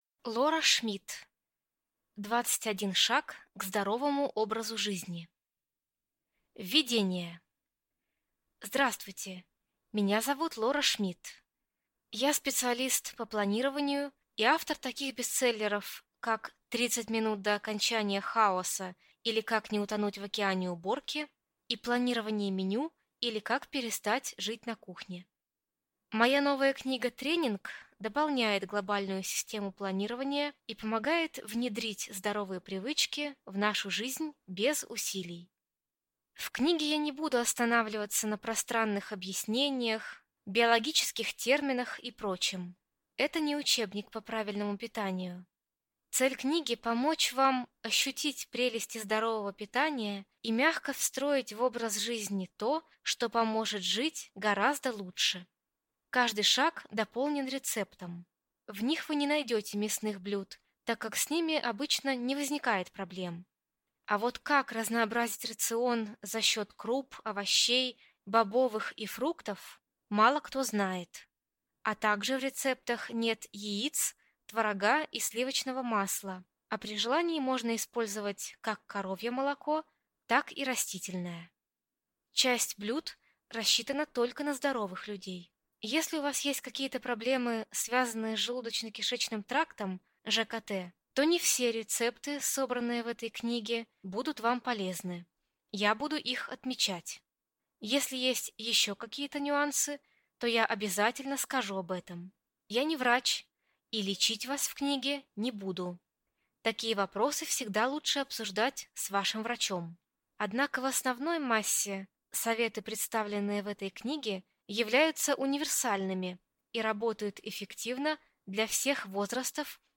Аудиокнига 21 шаг к здоровому образу жизни | Библиотека аудиокниг